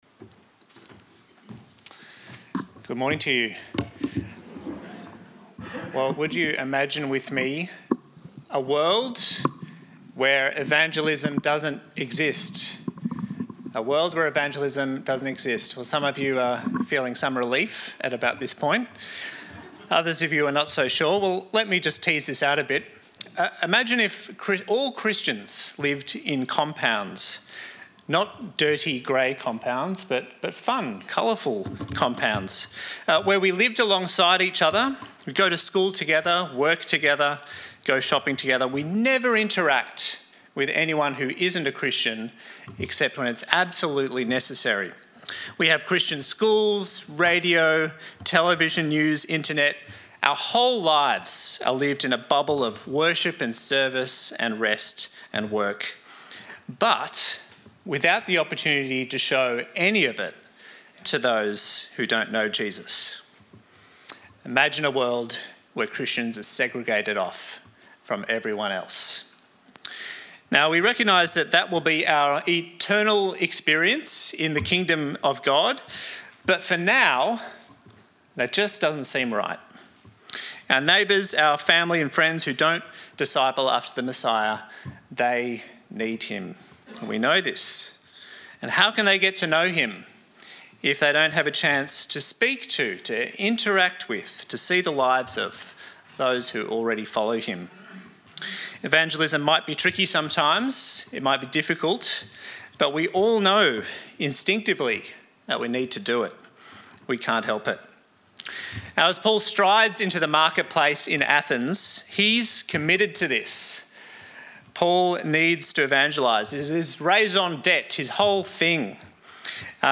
Sermons | St Alfred's Anglican Church